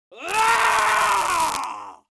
Screams Male 05
Screams Male 05.wav